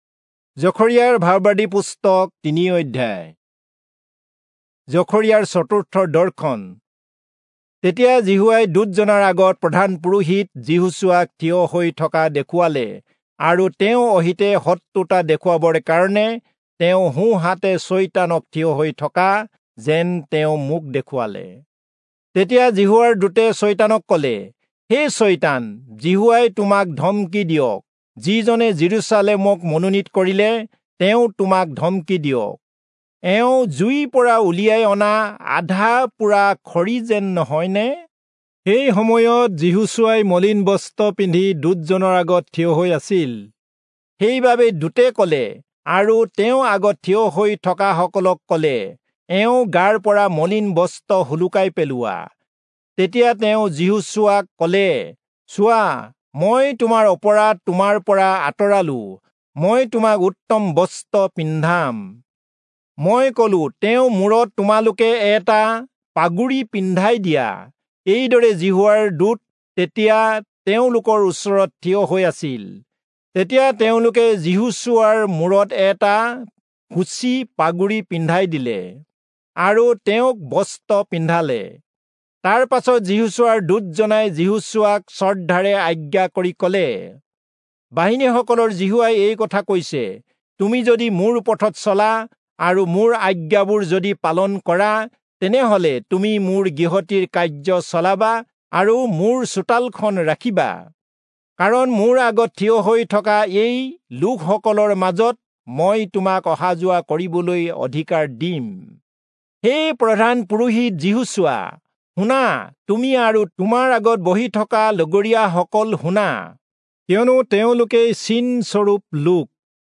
Assamese Audio Bible - Zechariah 1 in Ervml bible version